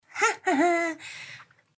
hahaha.mp3